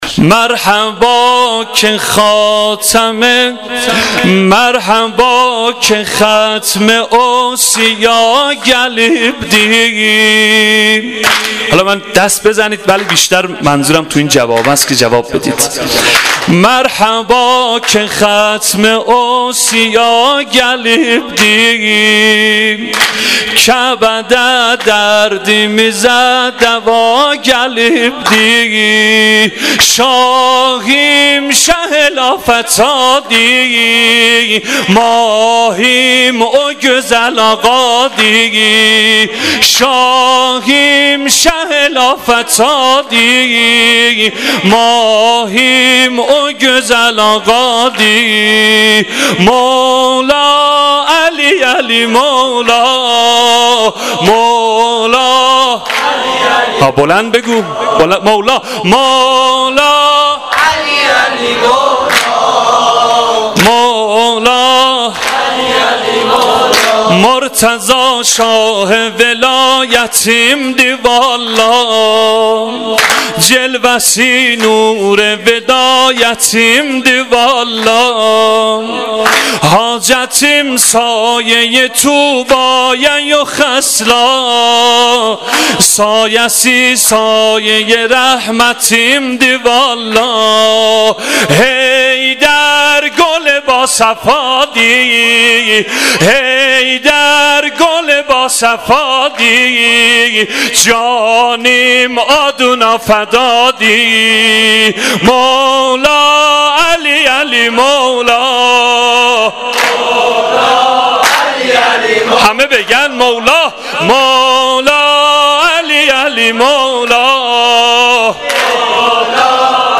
سرود _ شب میلاد امام علی (علیه السلام)